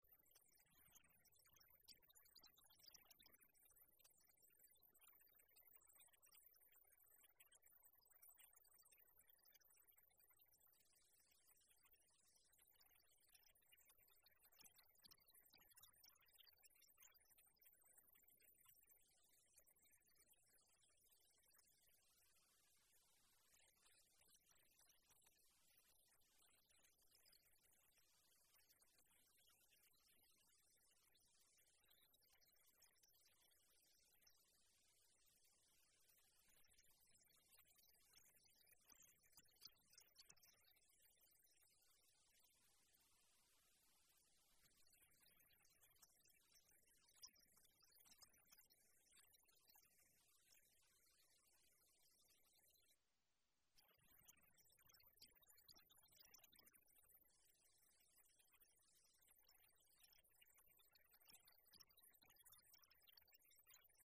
blaukehlchen.mp3